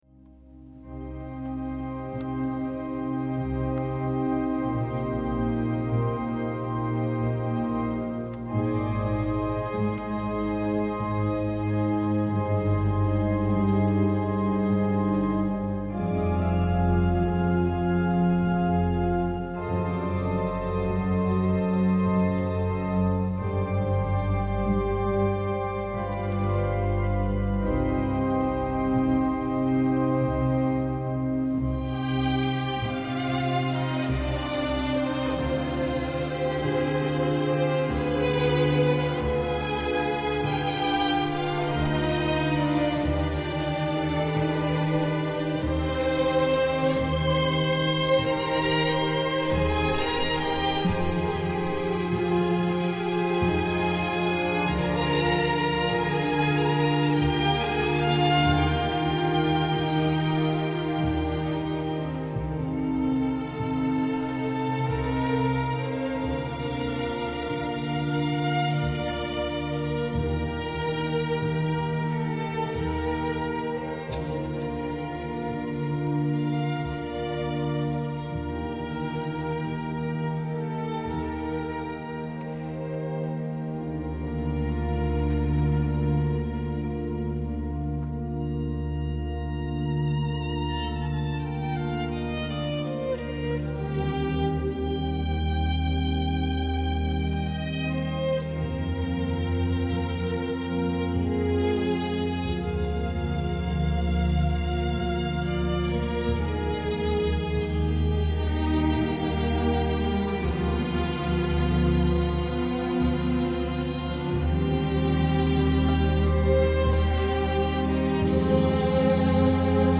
Konzert live aufgenommen im Hohen Dom zu Valladolid